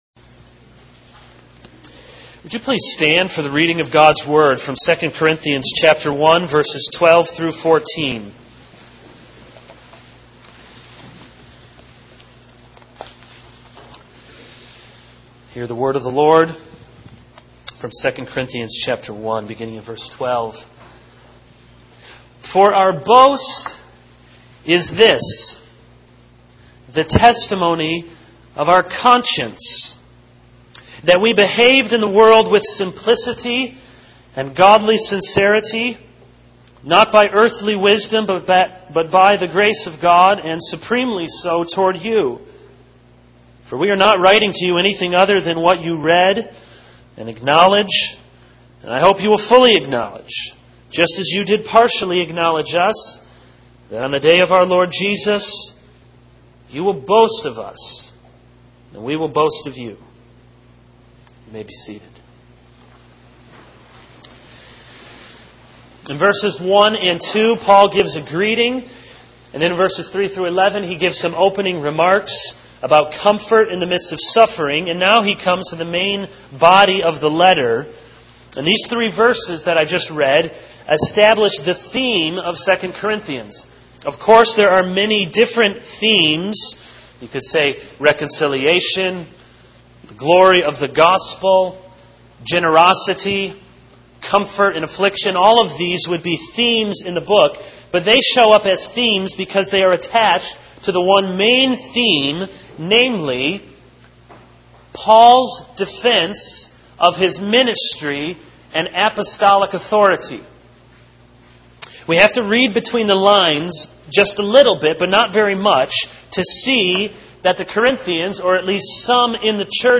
This is a sermon on 2 Corinthians 1:12-14.